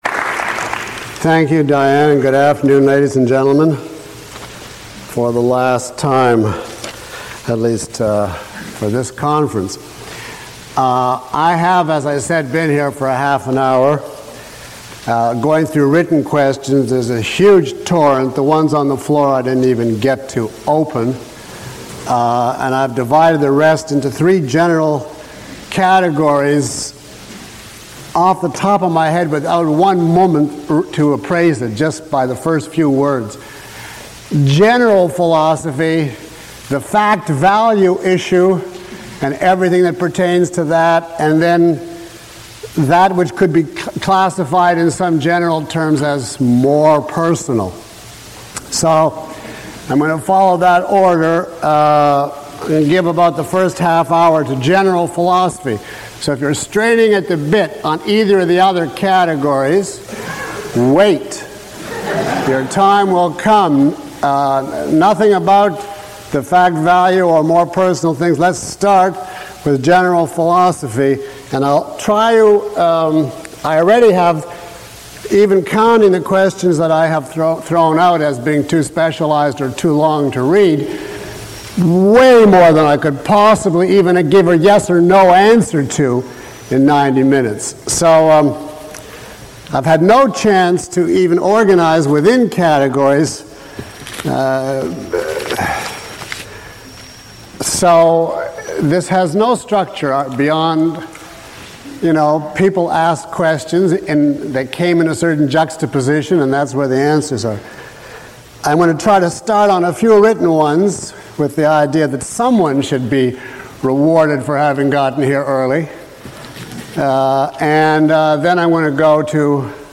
A question and answer session for course attendees.
Read more » In this lecture: This is a dedicated question and answer session with Dr. Peikoff.
Lecture 04 - Moral Virtue.mp3